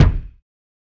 MB Kick (45).wav